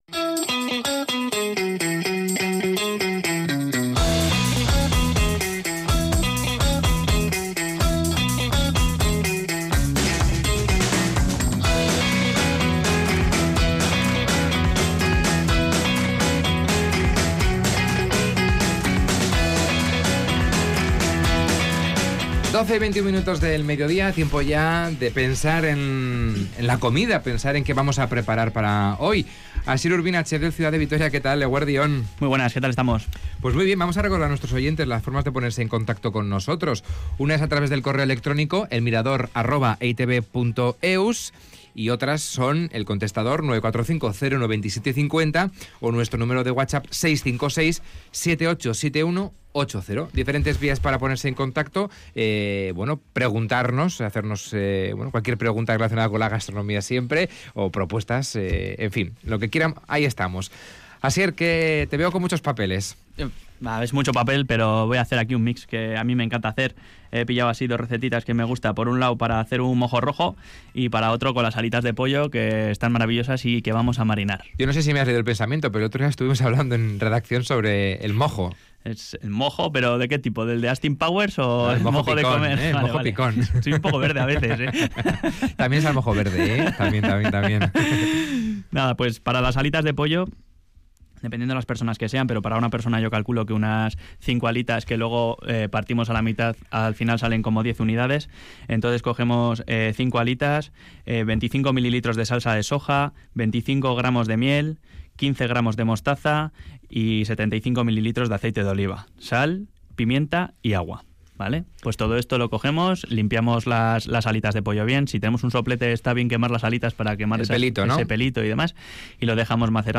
Y responde a las dudas de una oyente sobre la crema de higos.